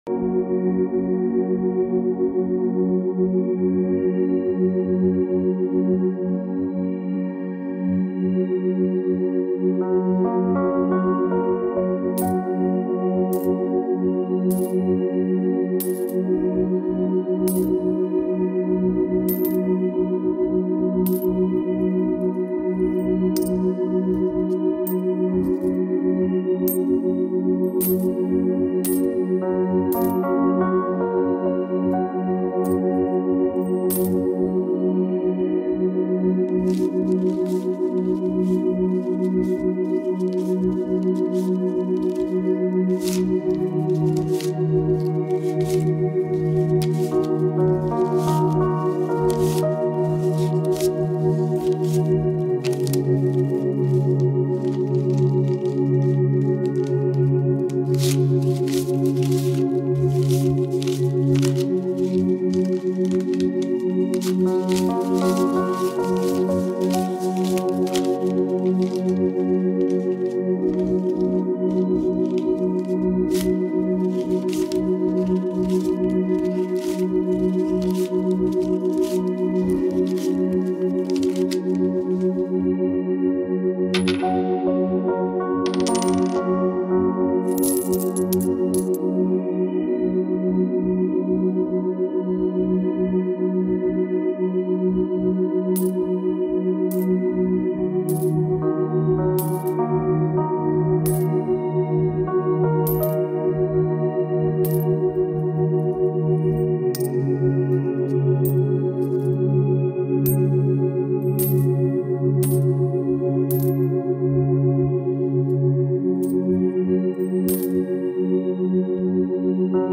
FRÉQUENCES VIBRATIORES